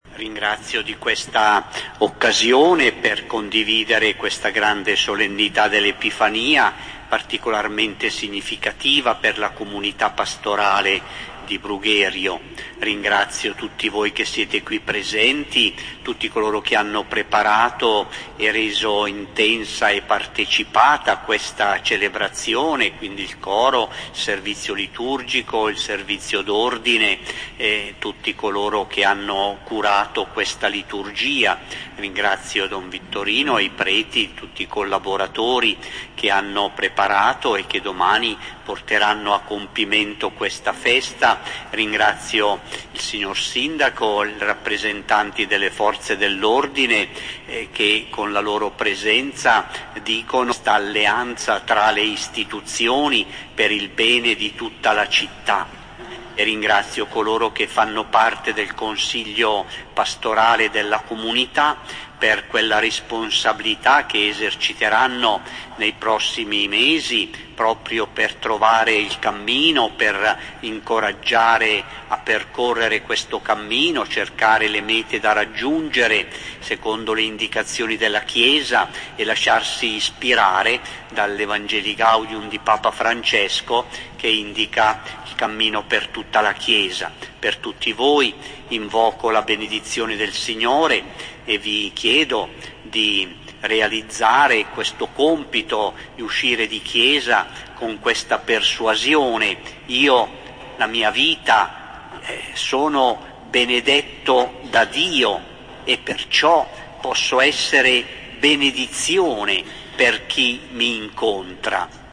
S.Messa Epifania 5 gennaio 2020 18:30 – audio
03-ringraziamenti-delpini.mp3